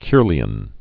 (kîrlē-ən)